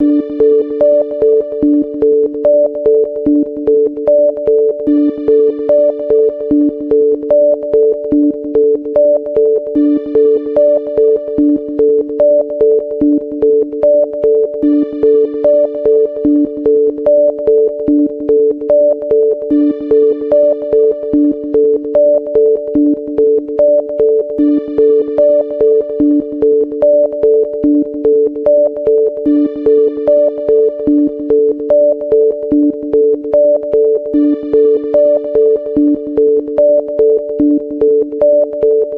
上品でシンプルな電子着信音。
電子音とメロディが融合した独特のサウンドで構成されており、特にビジネスシーンでの使用に適しています。